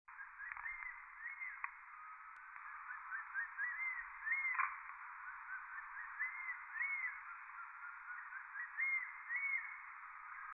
39-1扇平2012feb5大冠鷲1.mp3
物種名稱 大冠鷲 Spilornis cheela hoya
錄音地點 高雄市 六龜區 扇平
錄音環境 森林
行為描述 鳥叫
收音: 廠牌 Sennheiser 型號 ME 67